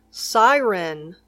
Pronunciación